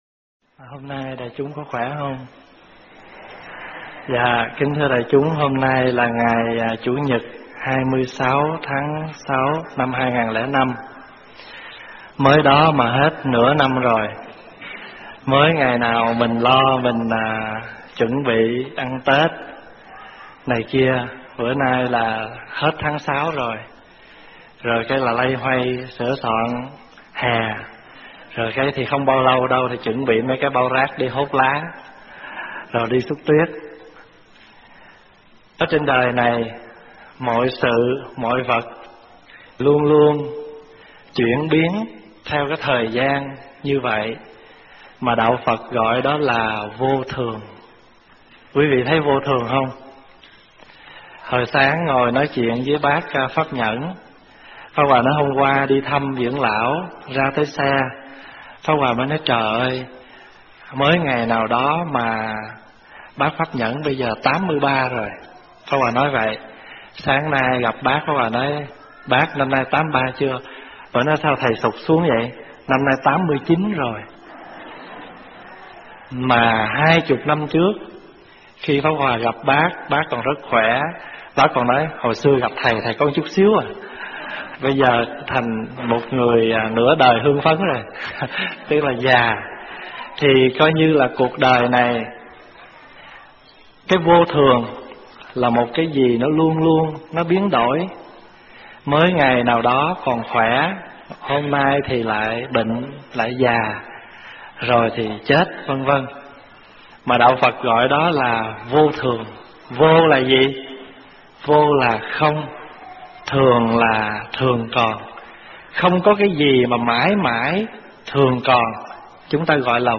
Mời quý phật tử nghe mp3 thuyết pháp 5 điều tổn phước không nên làm